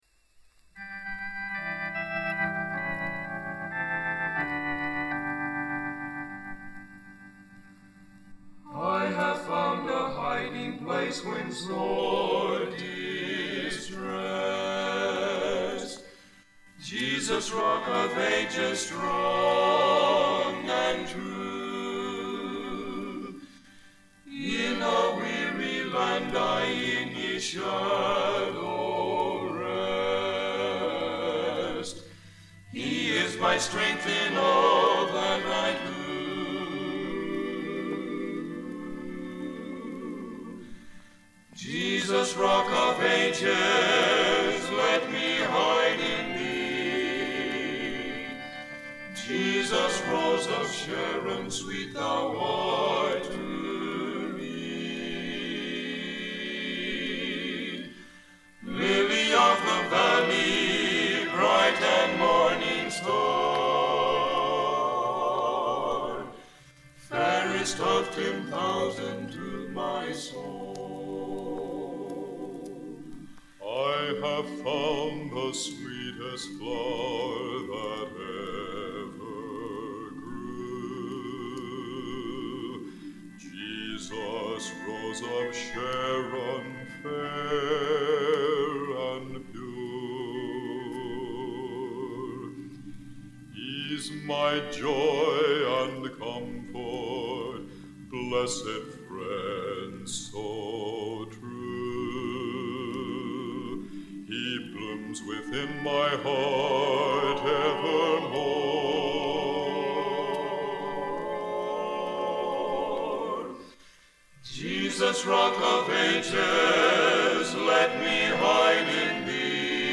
To God Be The Glory - BNC Collegiate Quartet